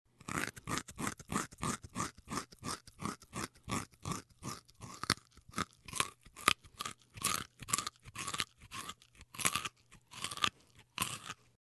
Звук, як заєць їсть овоч (моркву)